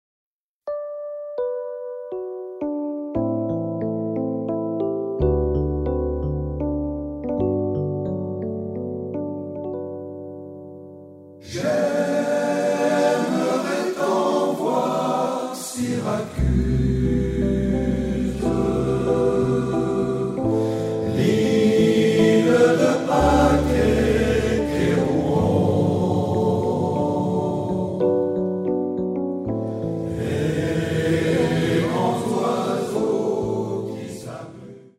Notre choeur
Extraits de notre répertoire